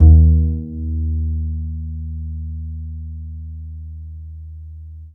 DBL BASS FN2.wav